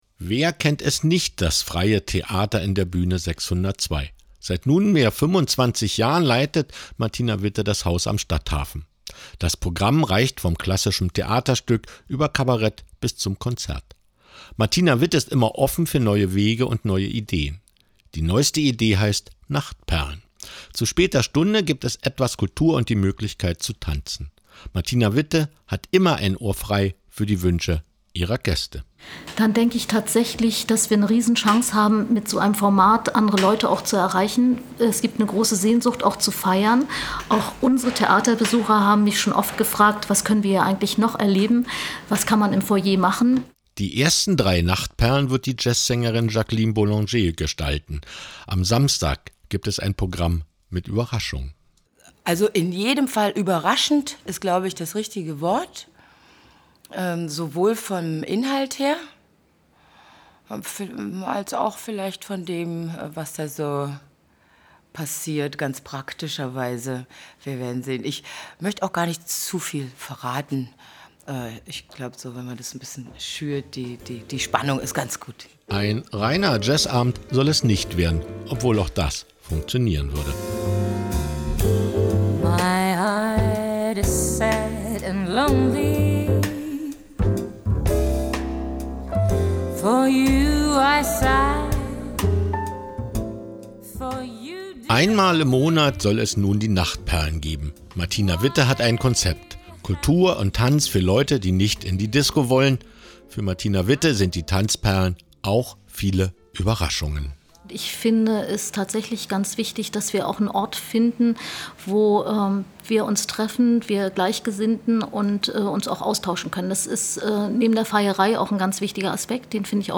Das Programm „Nachtperlen“ findet ab diesen Samstag einmal im Monat statt. Wir haben bei der Bühne 602 vorbeigeschaut: